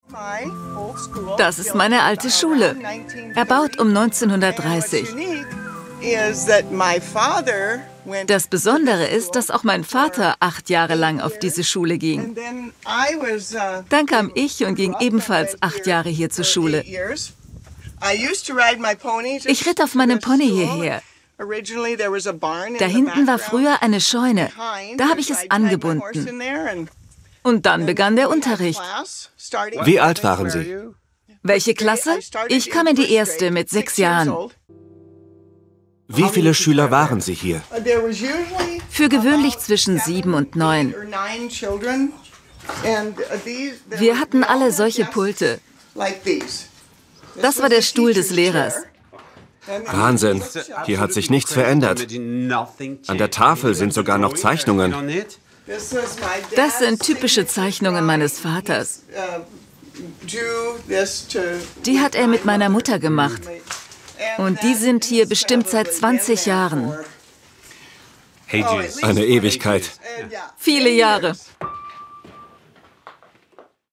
Doku